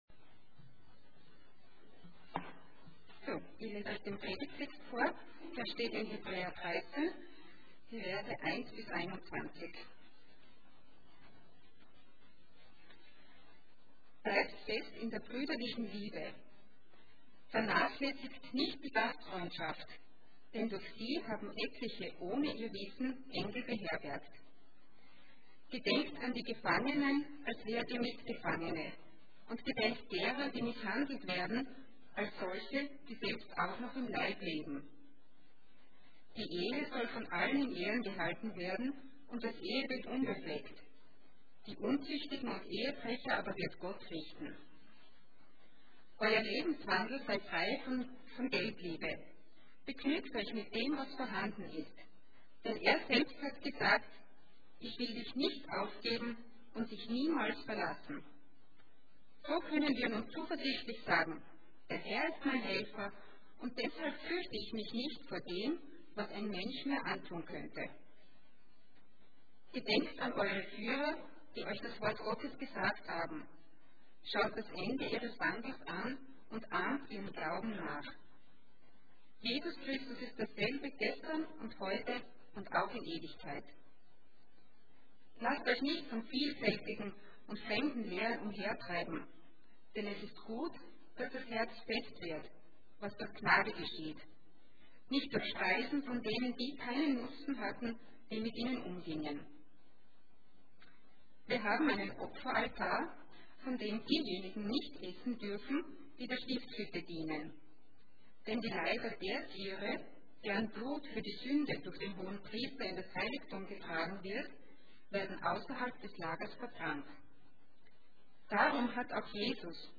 Allgemeine Predigten Passage: Hebrews 13:1-21 Dienstart: Sonntag Morgen %todo_render% Jesus ist besser « Gott